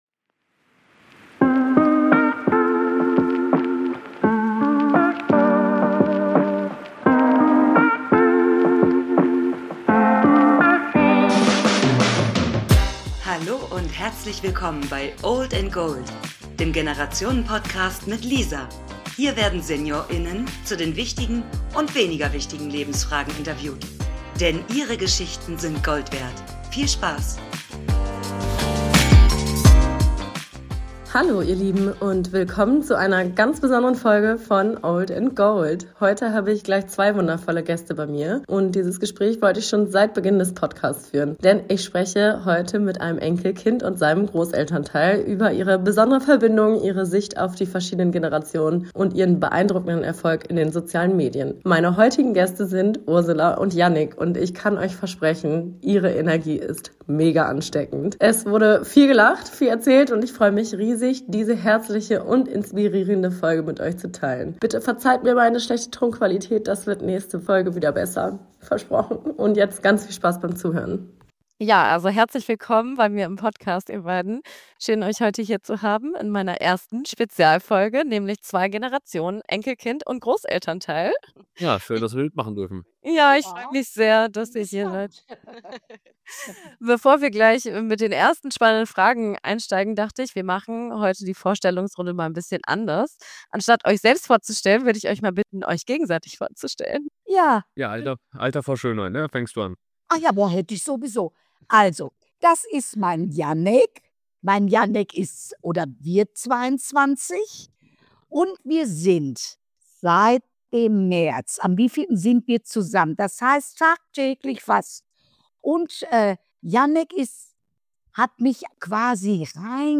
Seit Beginn von Old and Gold wollte ich ein Interview mit einem Enkelkind und einem Großelternteil führen und hier sind wir nun: die erste Spezialfolge mit zwei Gästen!